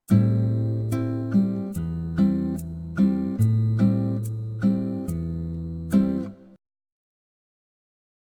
version réduite et compressée pour le Web